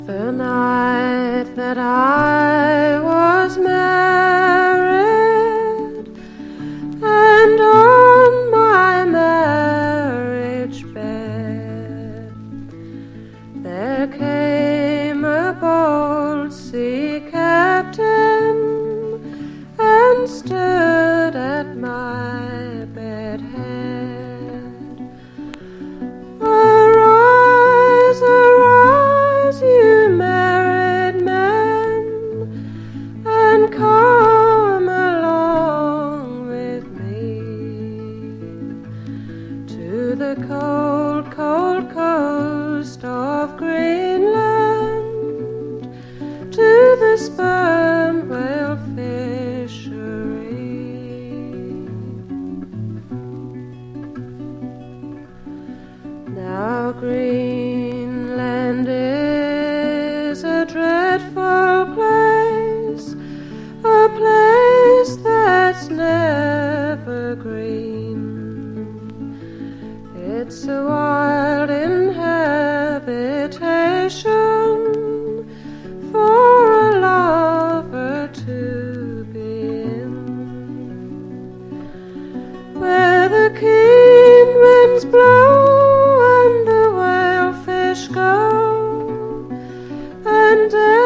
ブリティッシュ・フォーク・レジェンド！